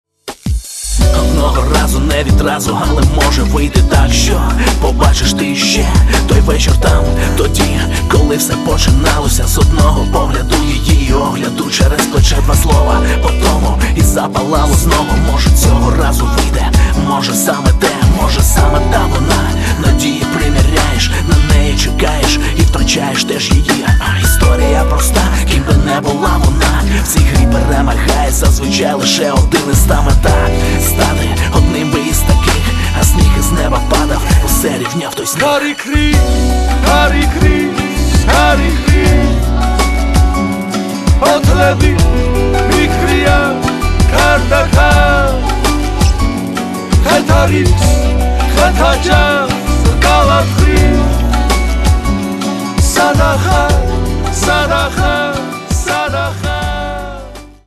Каталог -> Хип-хоп